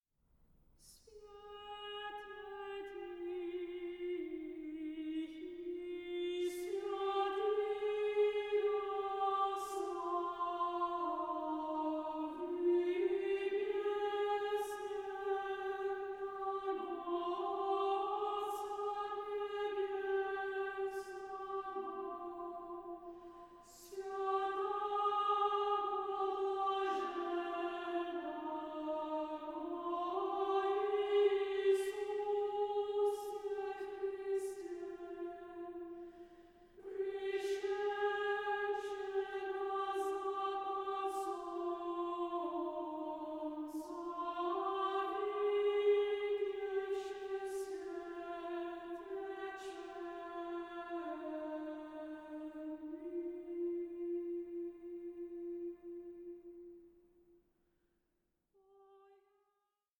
choral masterpiece